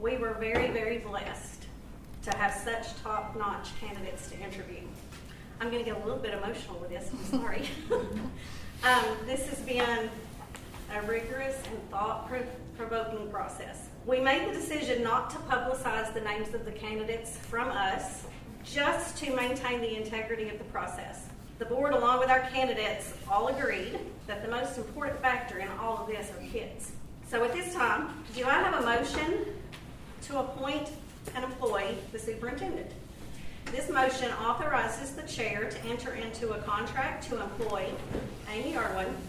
This was the statement of Barren County Board of Education Chairperson, Shelley Groce made before the central office boomed with applause at yesterday’s special called meeting.